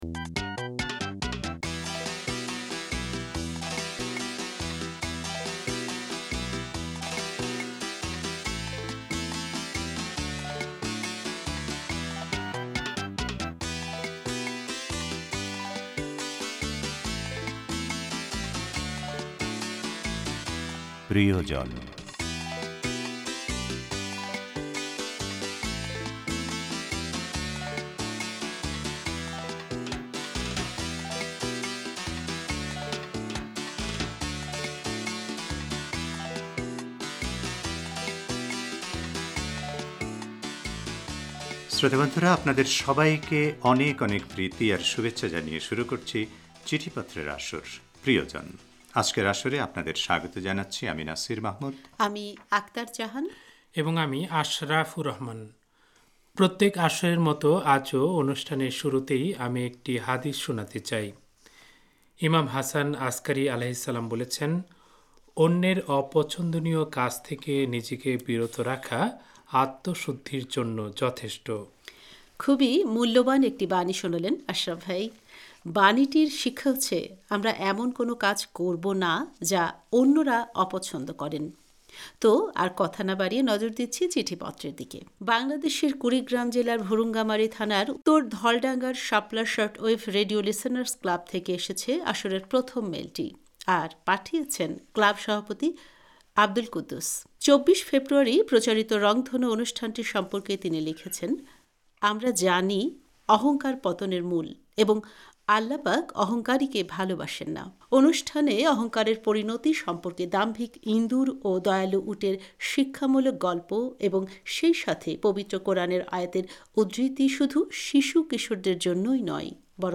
চিঠিপত্রের আসর 'প্রিয়জন'